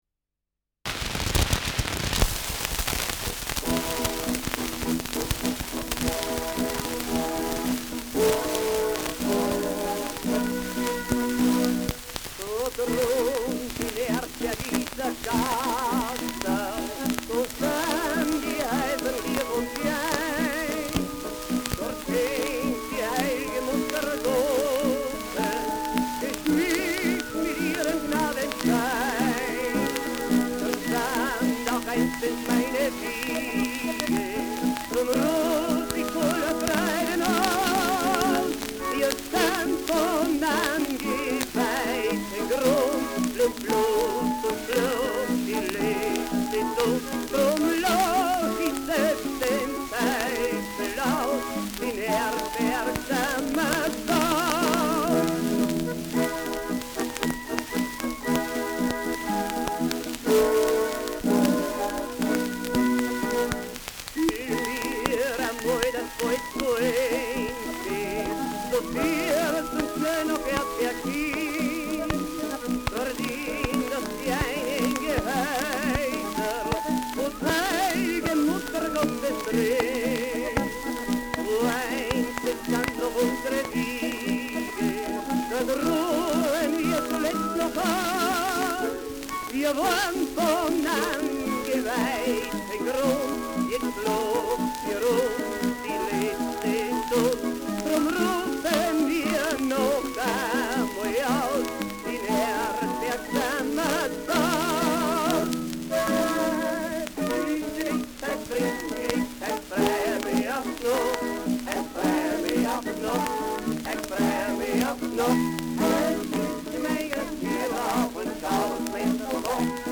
Schellackplatte
Besonders zu Beginn stark verrauscht : Durchgehend leichtes bis stärkeres Knacken : Gelegentlich stärkere Nadelgeräusche
Original Lanner-Quartett (Interpretation)